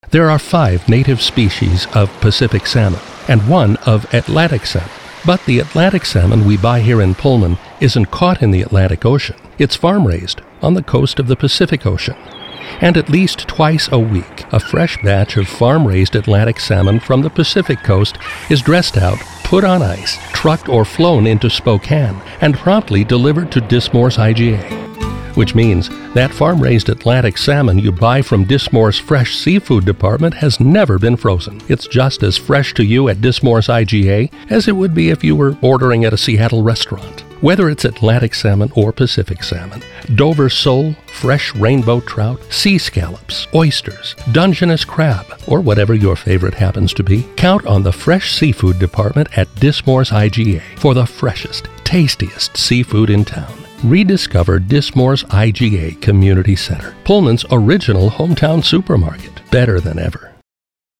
Variants of the commercial in question have aired on local stations for the past six or seven years.